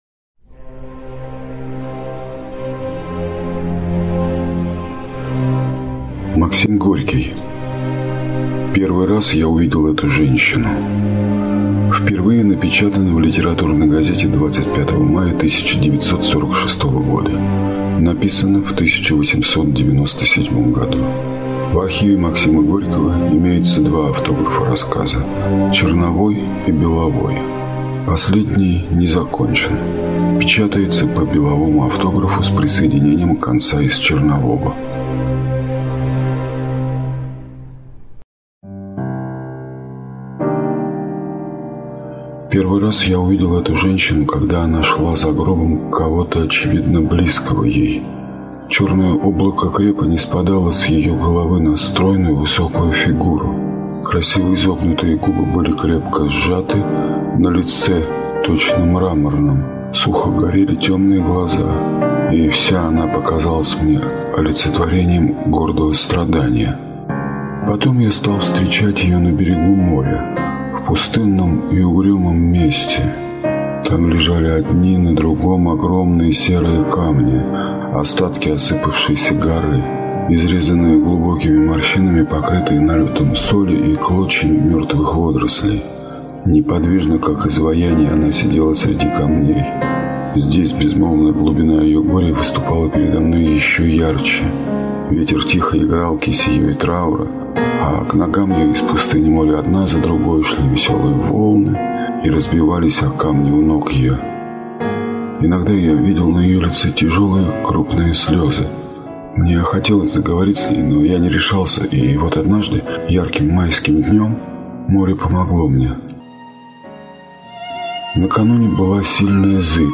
На данной странице вы можете слушать онлайн бесплатно и скачать аудиокнигу "Первый раз я увидел эту женщину" писателя Максим Горький.